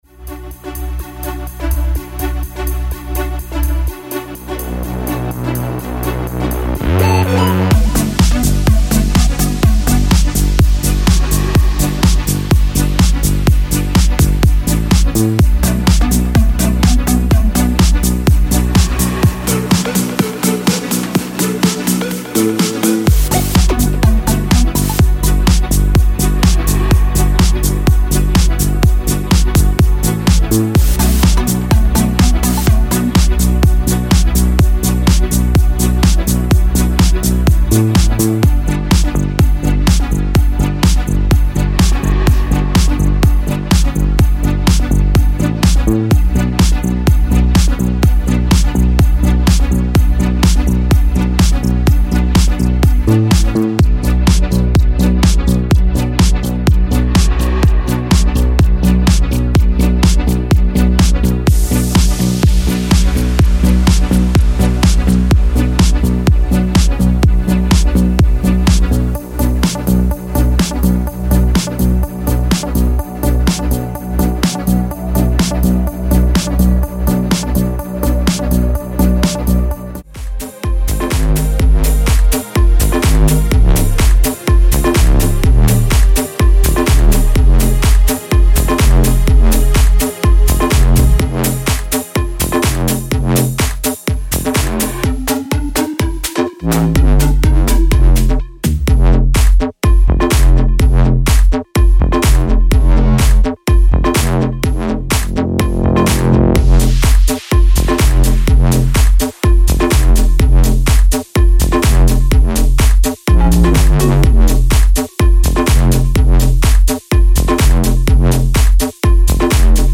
用于Ableton Live +血清的高科技最小Techno声音包
高科技最小技术–声音包
• ✓鼓 采样包， 包含135个以上循环和一声。